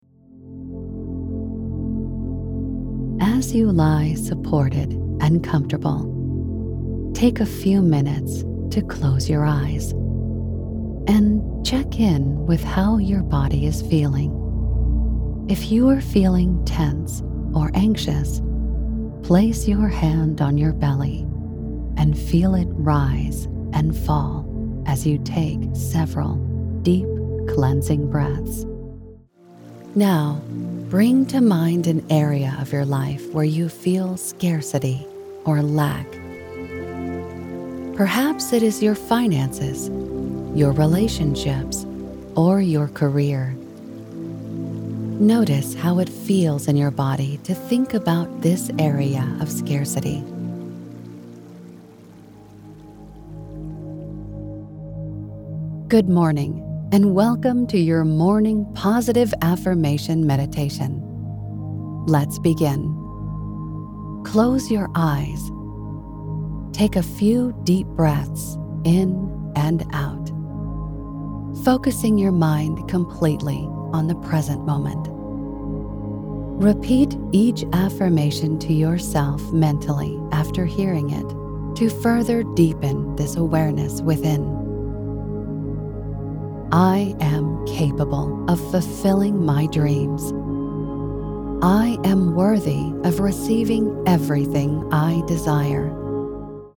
Warm, Natural and Experienced!
Meditation / Affirmations
North American General